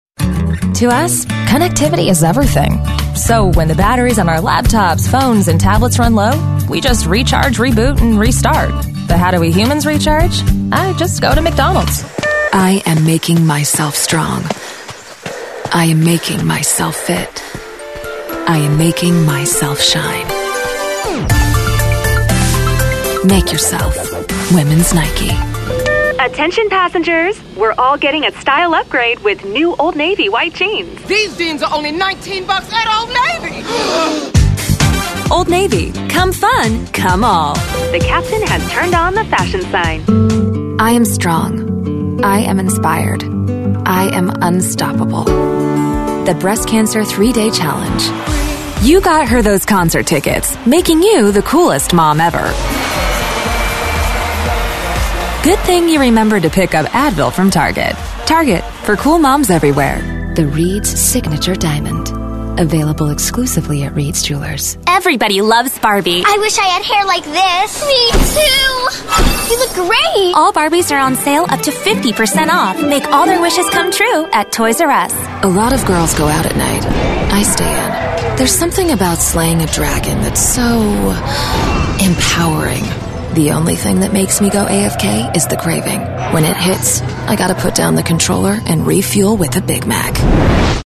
Advertising Demo